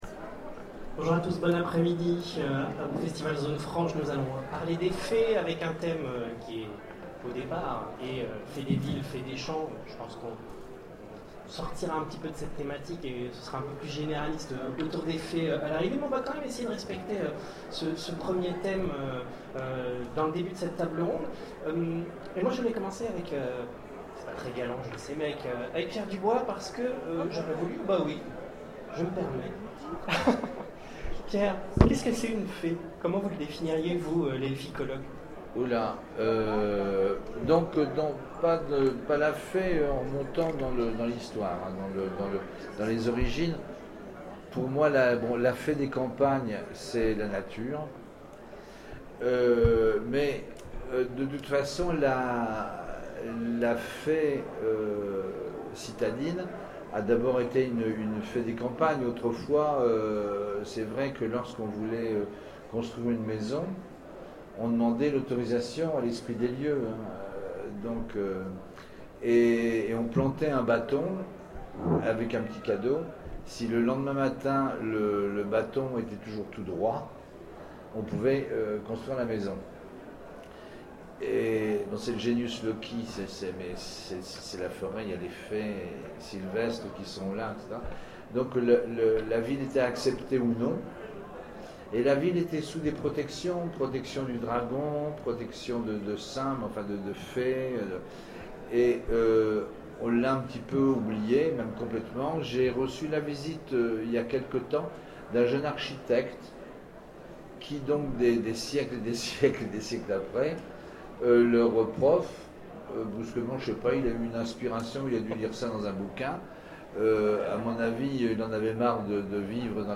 Zone Franche 2014 : Conférence Fées des villes et fées des champs